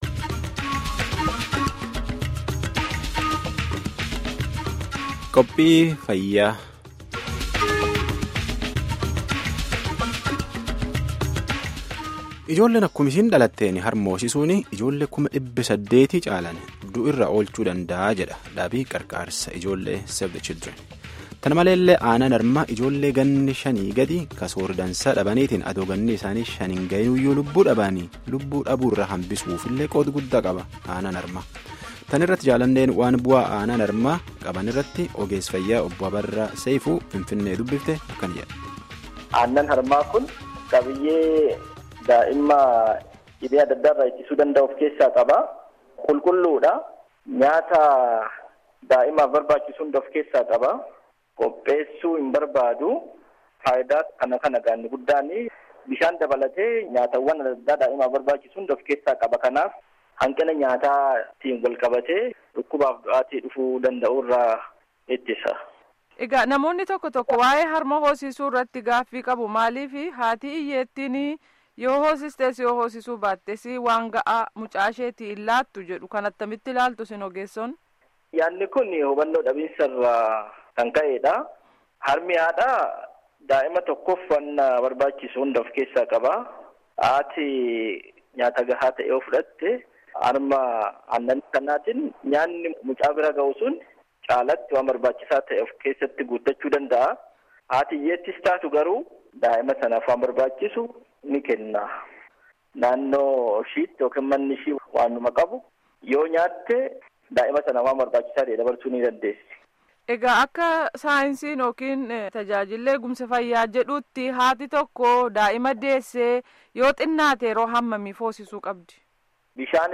Gaaffii fi Deebii dhaggeeffadhaa